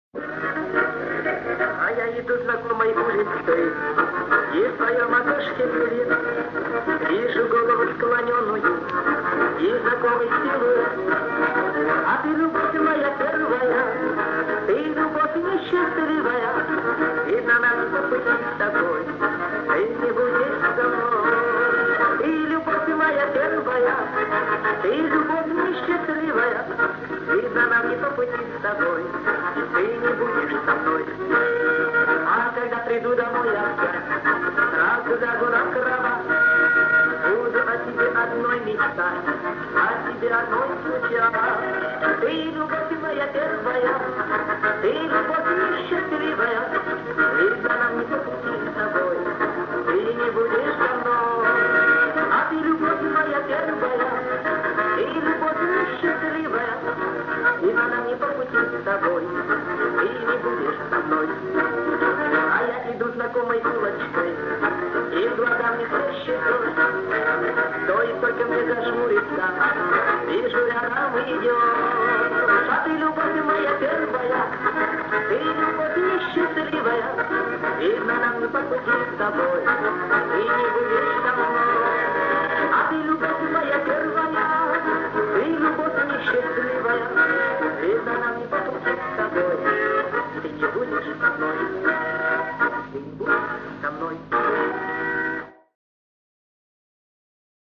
Есть в плохом качестве  исполнитель другой